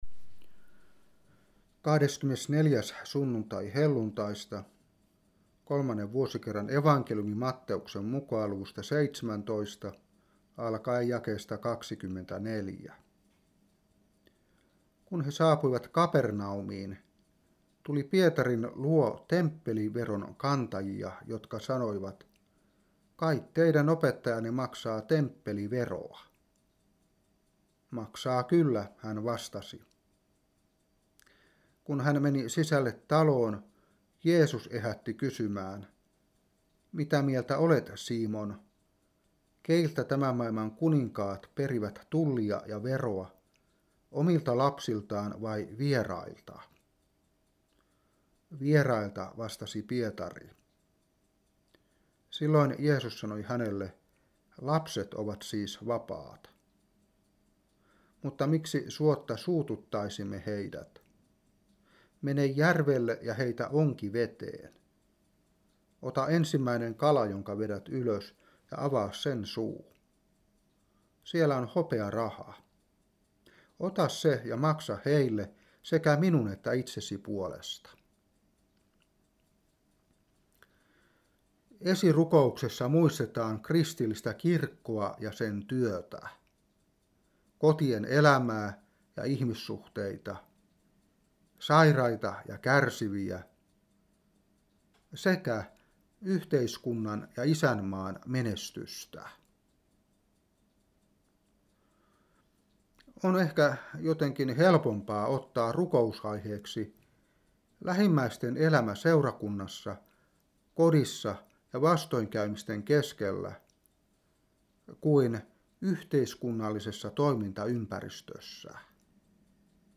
Saarna 2012-11.